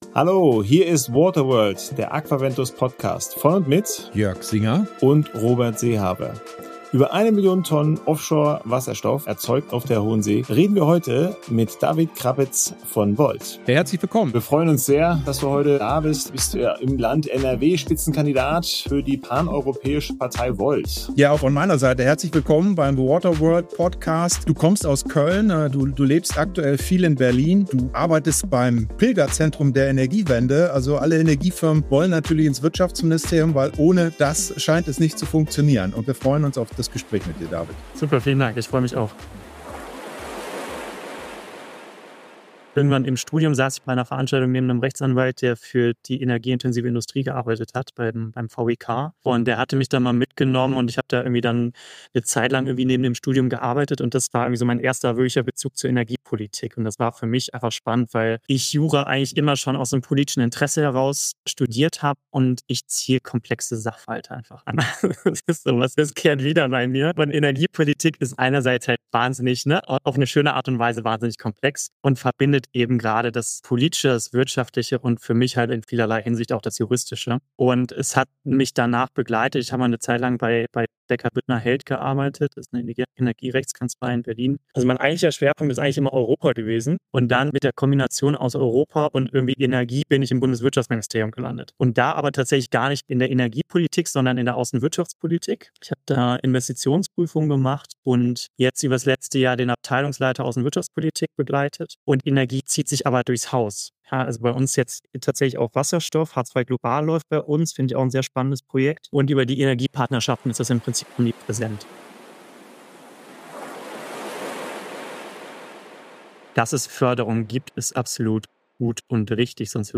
Im Gespräch mit den Hosts stellt er die energiepolitischen Vorstellungen im Vorfeld der Bundestagswahl vor und fordert u.a. ein neues Förderregime, das wirklich auf einen europäischen Energiemarkt abzielt.